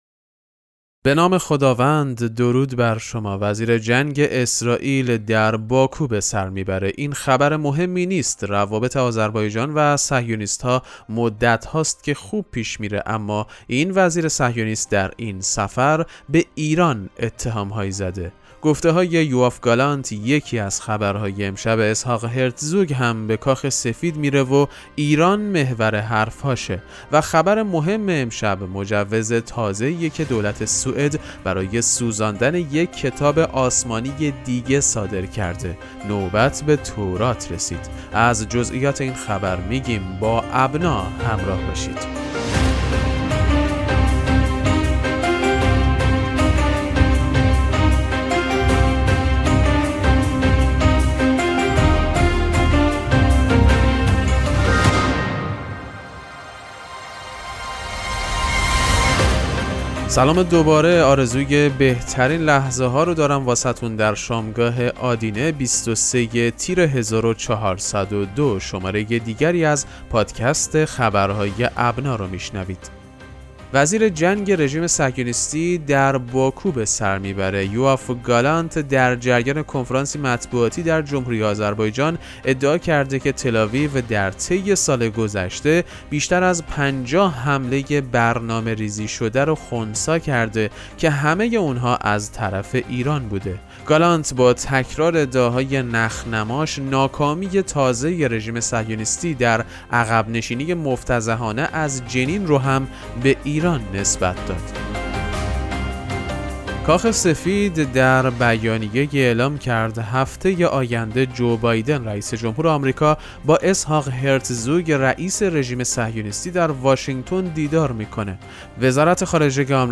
پادکست مهم‌ترین اخبار ابنا فارسی ــ 23 تیر 1402